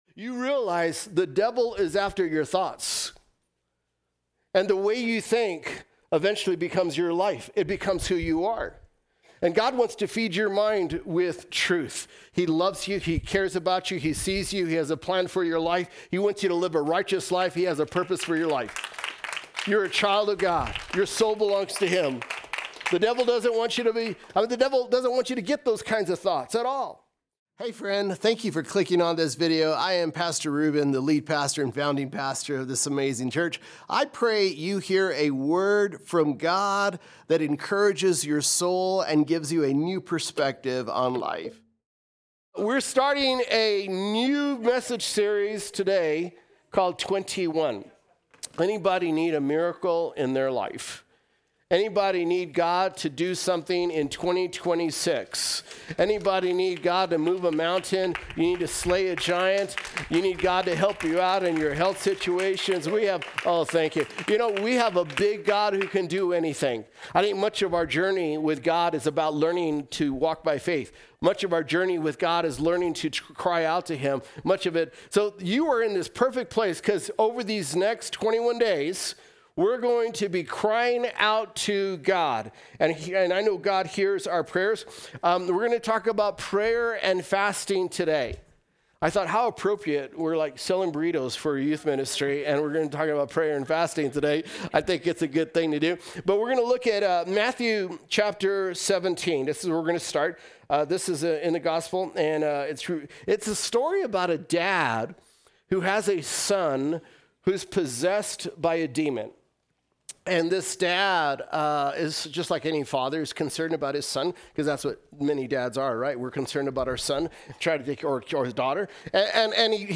Listen to weekend messages that will inspire, encourage, and help you grow in your faith with Jesus from ThornCreek Church.